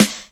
• Trebly Snare A Key 79.wav
Royality free snare drum tuned to the A note. Loudest frequency: 2404Hz
trebly-snare-a-key-79-qKh.wav